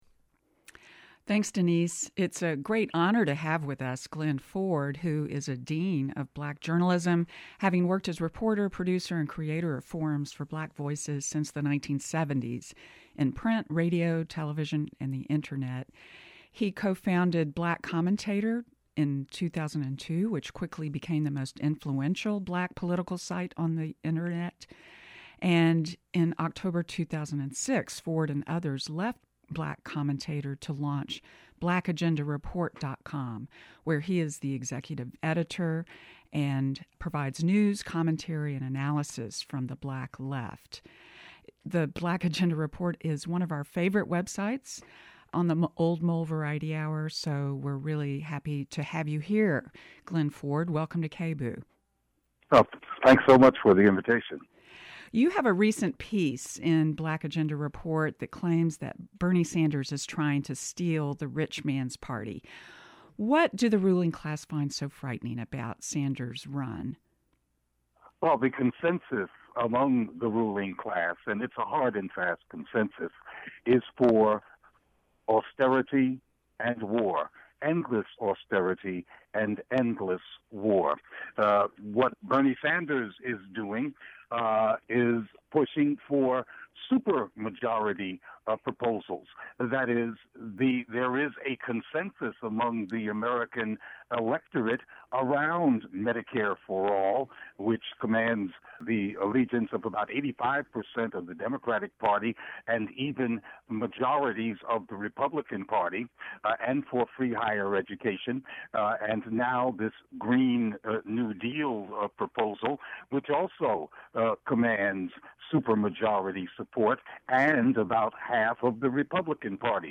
Program:: Old Mole Variety Hour